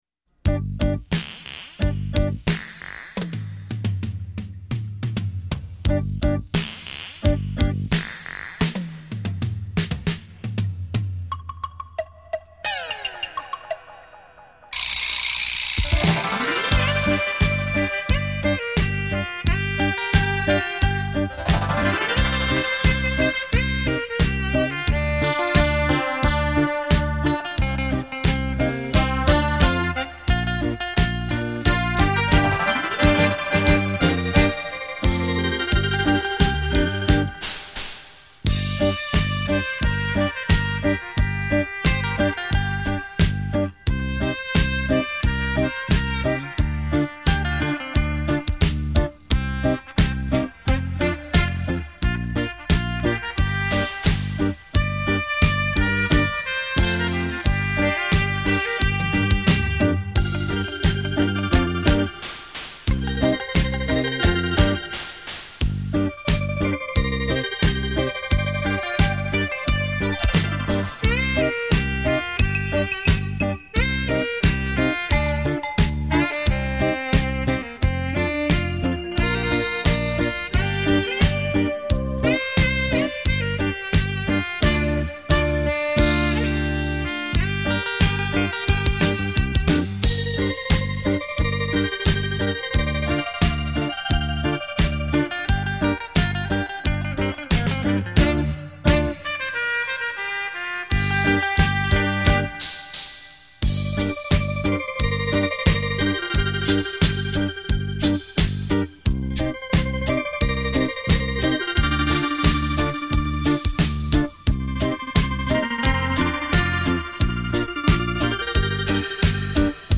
迪斯可